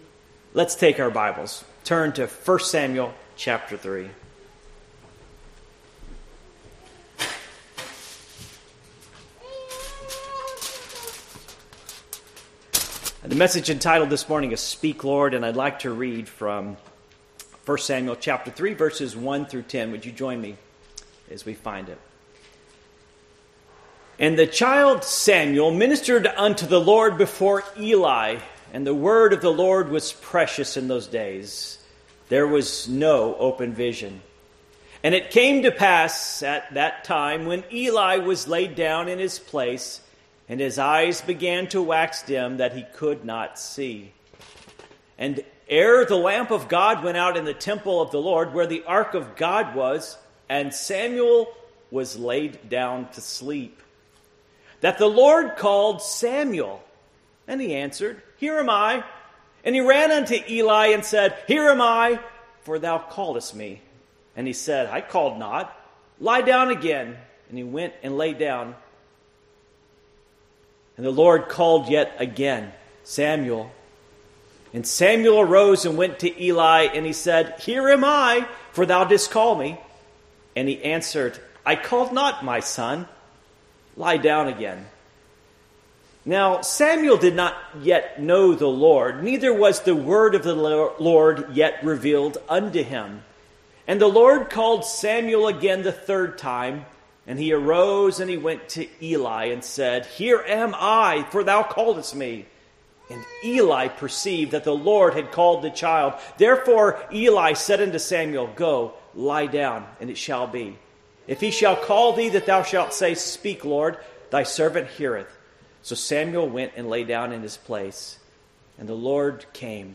Divine Reversal Passage: 1 Samuel 3:1-10 Service Type: Morning Worship 1 Samuel 3:1-10 1 And the child Samuel ministered unto the LORD before Eli.